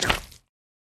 Minecraft Version Minecraft Version snapshot Latest Release | Latest Snapshot snapshot / assets / minecraft / sounds / block / honeyblock / step3.ogg Compare With Compare With Latest Release | Latest Snapshot
step3.ogg